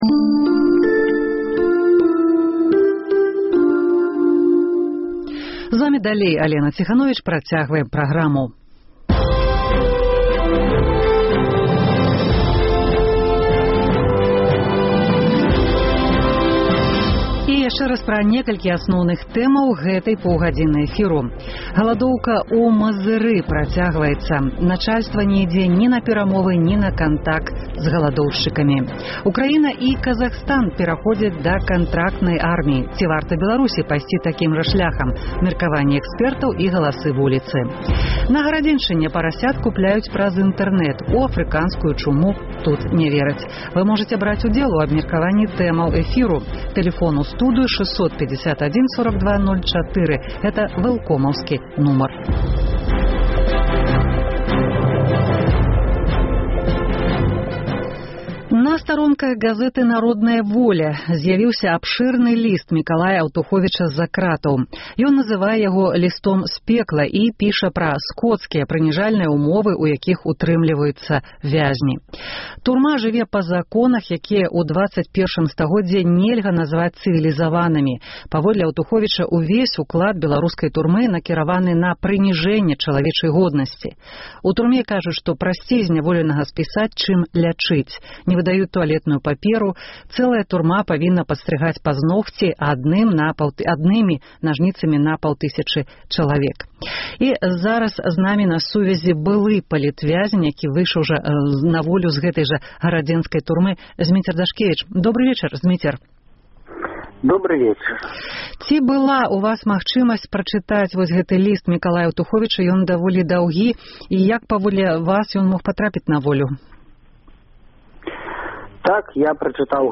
Меркаваньні экспэртаў і галасы вуліцы.